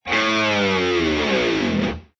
descargar sonido mp3 guitarra